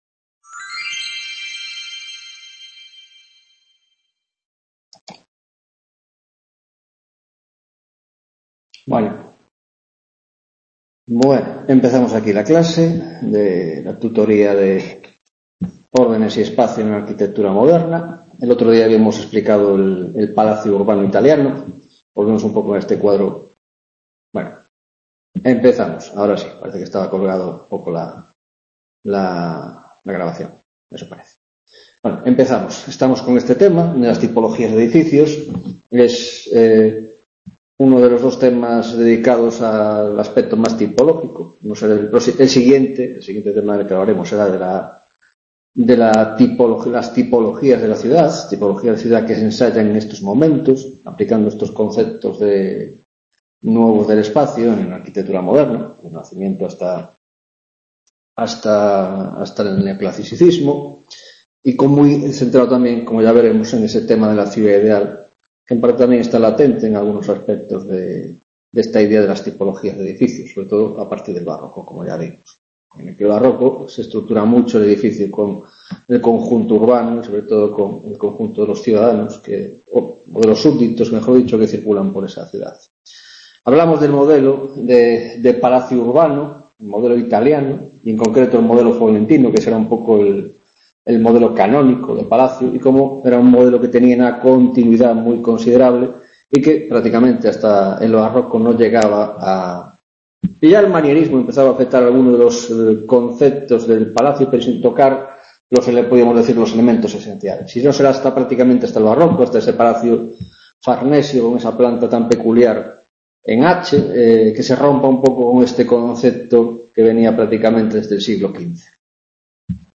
9ª Tutoria de la asignatura: Órdenes y Espacio en la Arquitectura Moderna - 5 Modelos de Edificios: El Palacio: El Hôtel frances y la villa suburbana